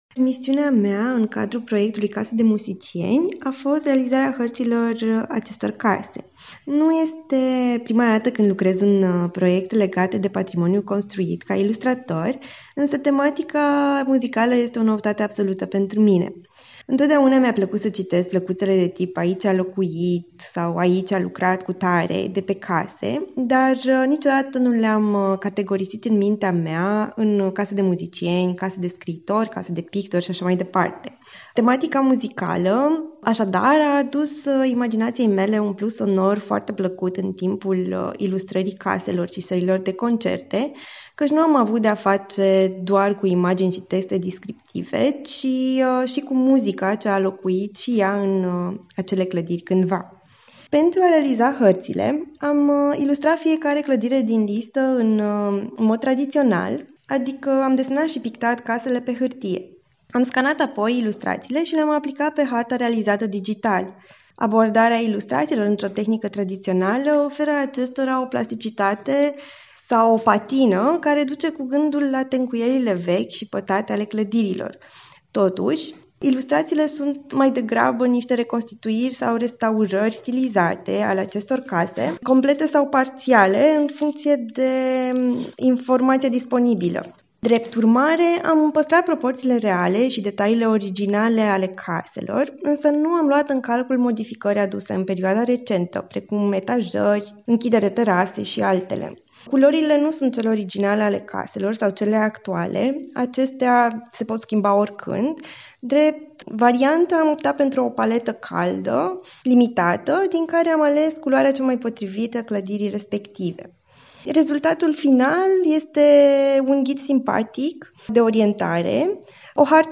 interviu în exclusivitate pentru Radio Timișoara